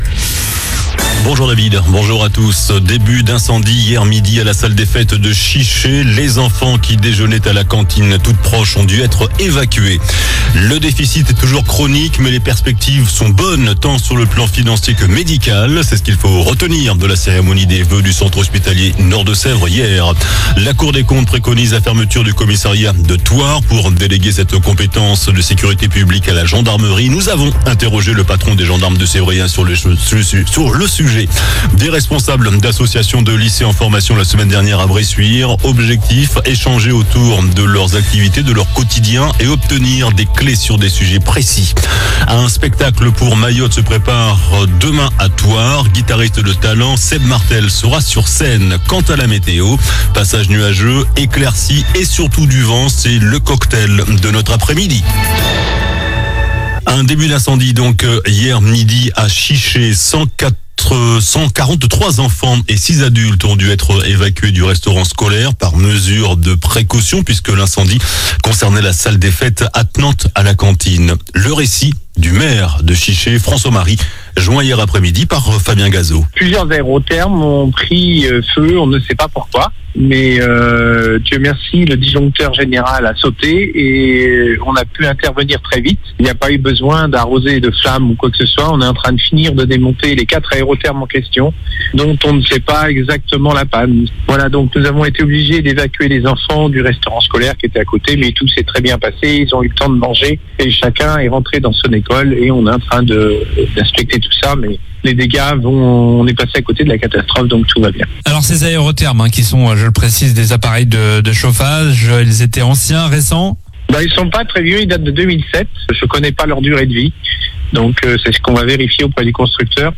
JOURNAL DU MARDI 28 JANVIER ( MIDI )